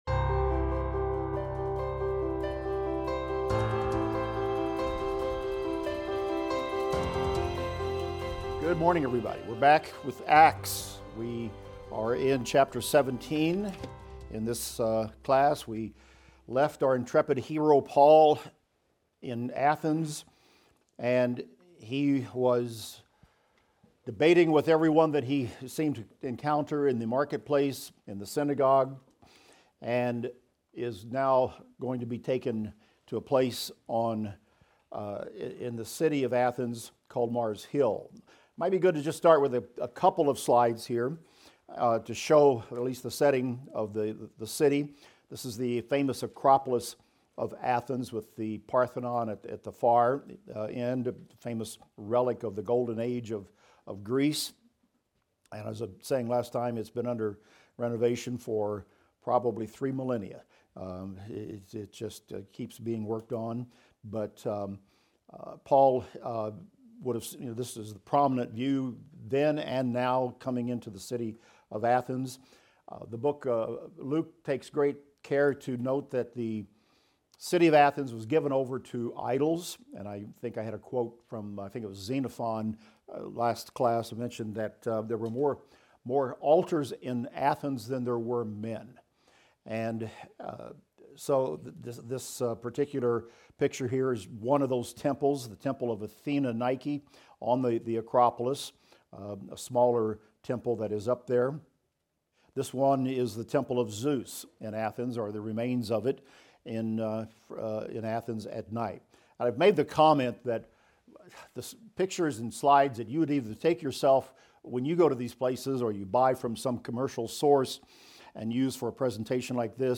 In this class we will discuss Acts 17:19-25 and continue looking at Paul's interactions with the Epicurean and Stoic philosophers in Athens.